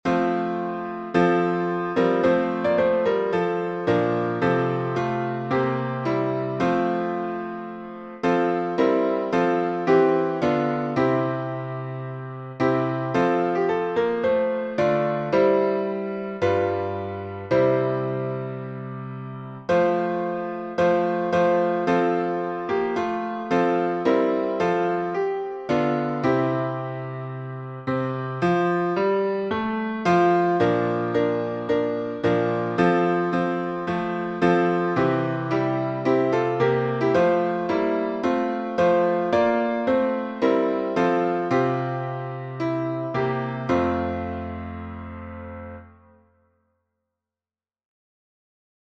O for a Thousand Tongues to Sing — F major — Lyngham.